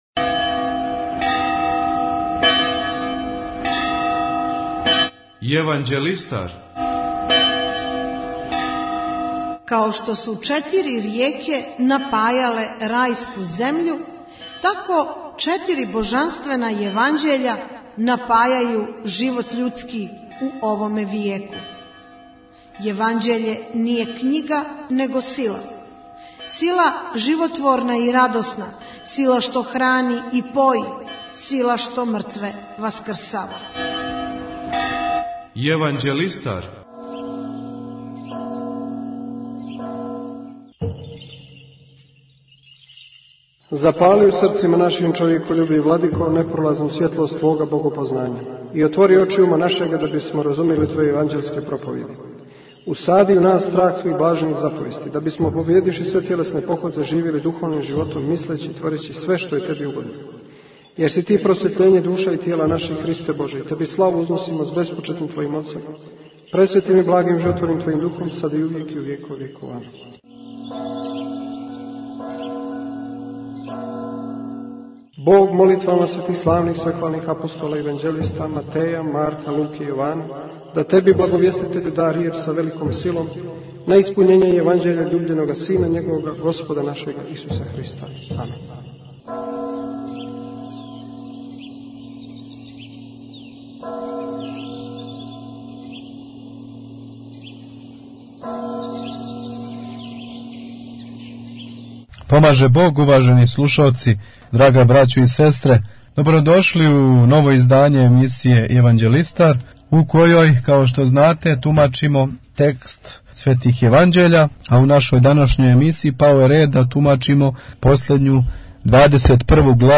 У овој емисији ишчитаваћемо и тумачити Двадесетпрву главу светог јеванђеља по Јовану.